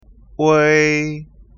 uei ออกเสียง u ก่อน แล้วออกเสียง ei ซึ่งก็คือ u ➜ ei = uei เวลาเขียนจะลดรูปเหลือแค่ ui แต่ในการออกเสียงจะต้องออกเสียงเป็น uei ไม่ใช่อ่านว่า อุย แต่ต้องว่า เอวย (หากไม่มีพยัญชนะต้นจะเขียนเป็น wei)